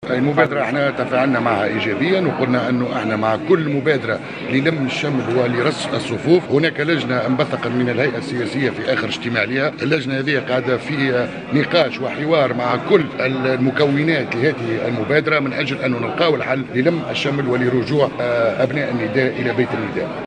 أكد القيادي بنداء تونس عبد العزيز القطي في تصريح اعلامي اليوم الخميس 31 مارس 2016 أنه مع كل مبادرة للم شمل أبناء نداء تونس ورص الصفوف على حد قوله.